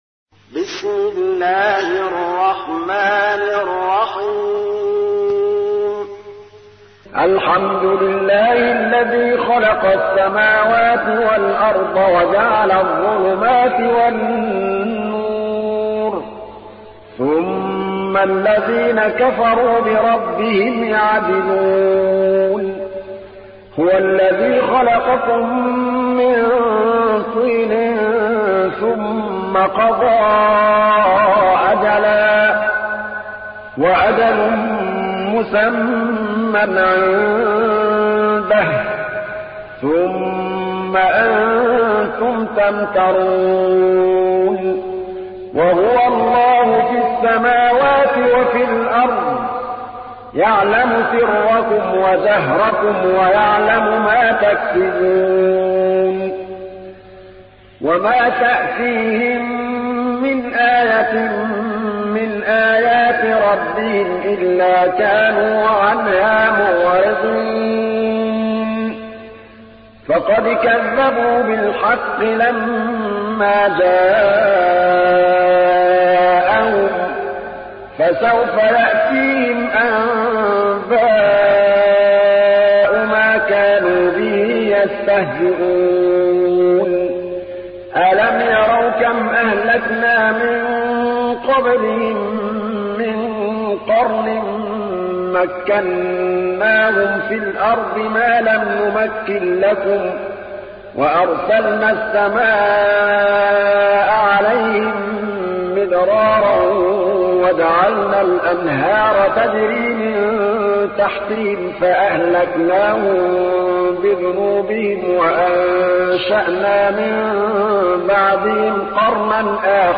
تحميل : 6. سورة الأنعام / القارئ محمود الطبلاوي / القرآن الكريم / موقع يا حسين